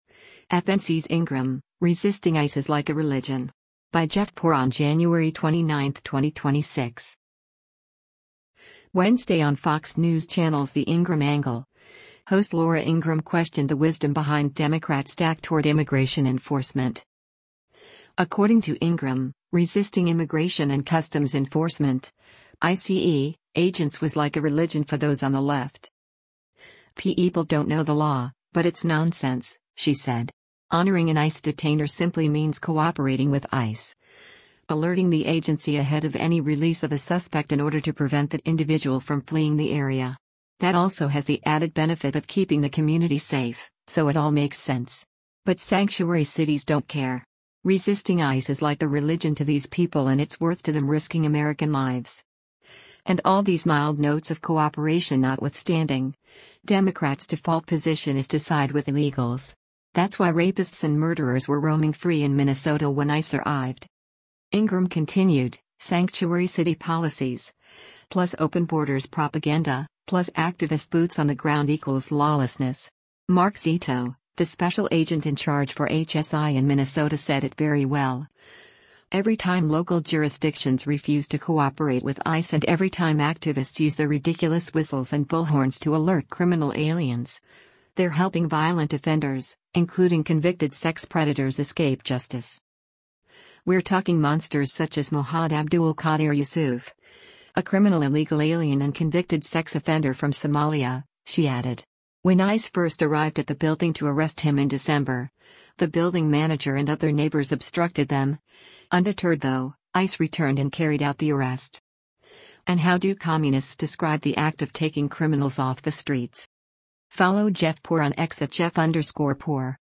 Wednesday on Fox News Channel’s “The Ingraham Angle,” host Laura Ingraham questioned the wisdom behind Democrats’ tack toward immigration enforcement.